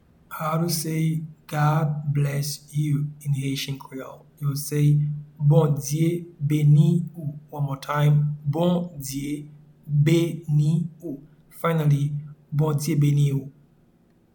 Pronunciation and Transcript:
God-bless-you-in-Haitian-Creole-Bondye-beni-ou.mp3